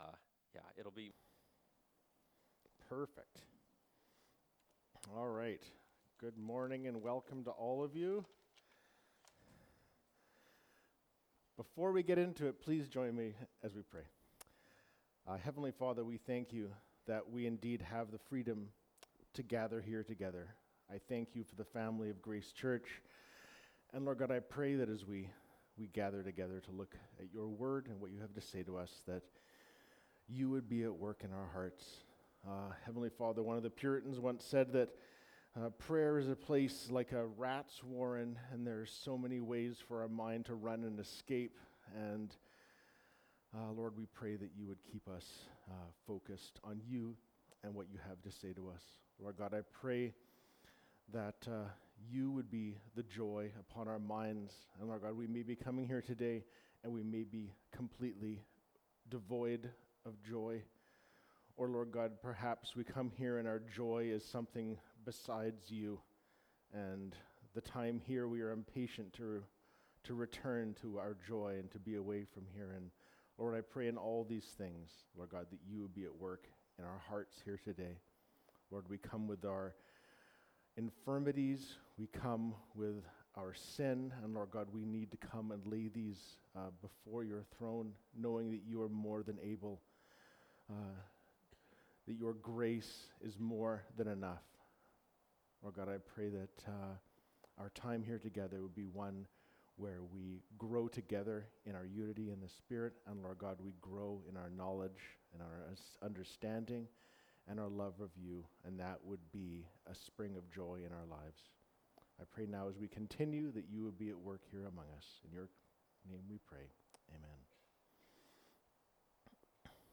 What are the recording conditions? Mar 17, 2024 Your Sorrow Will Turn Into Joy (John 16:16-24) MP3 SUBSCRIBE on iTunes(Podcast) Notes Discussion Sermons in this Series This sermon was recorded in Salmon Arm and preached in both Salmon Arm and Enderby.